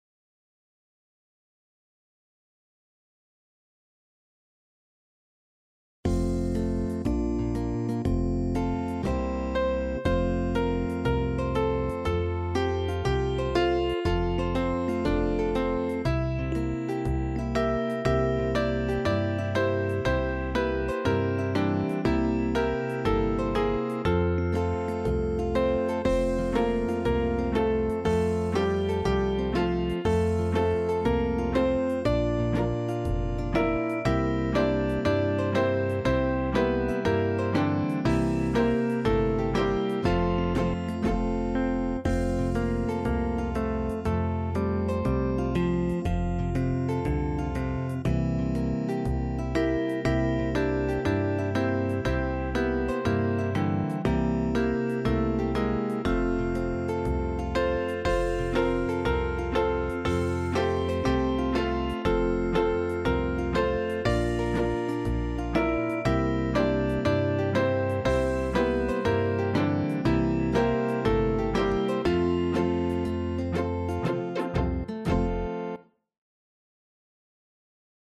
8-beat intro.